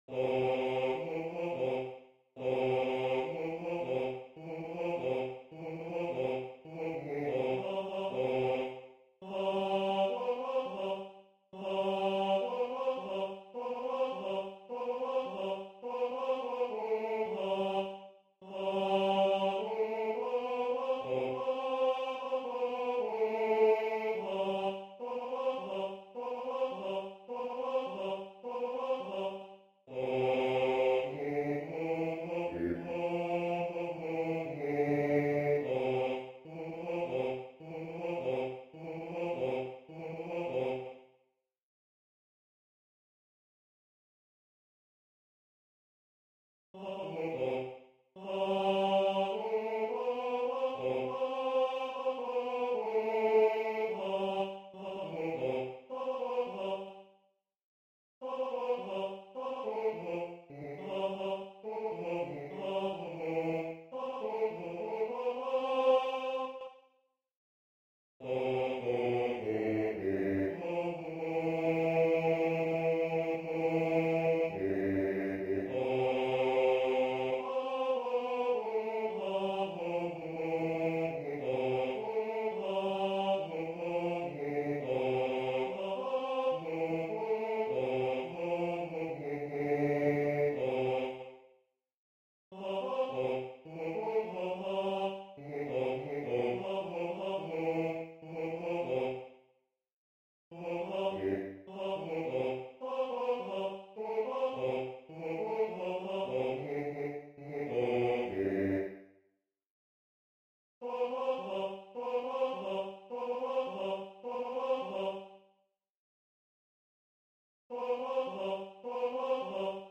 Partitions et enregistrements audio séquenceur du morceau Halleluja, de Georg Friedrich Haendel, Classique.
Compositeur: Georg Friedrich Haendel Genre: Classique Partitions: Choeur 4 voix SATB Fichier Finale: Choeur 4 voix SATB Fichier Midi: Choeur 4 voix SATB Enregistrements: Choeur Soprano Alto Ténor Basse
hallelujah_basse.mp3